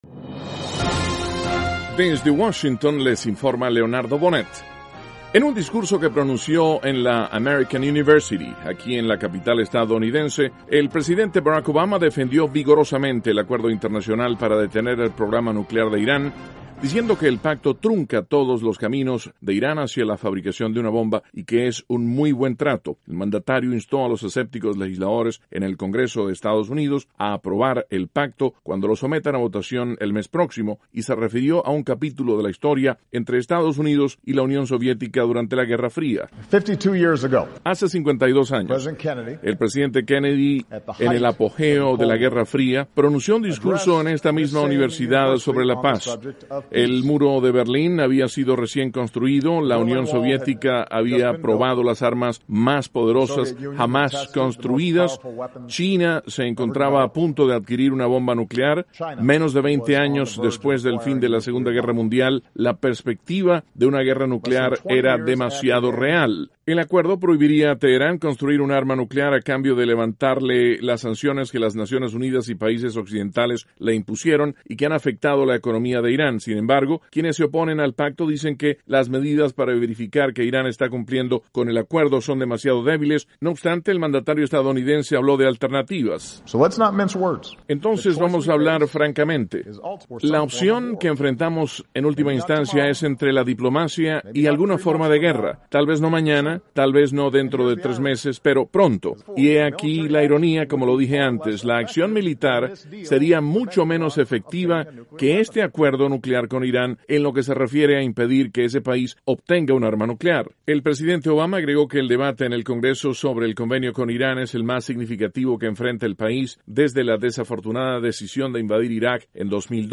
VOA: Noticias de la Voz de América - Miércoles, 5 de agosto, 2015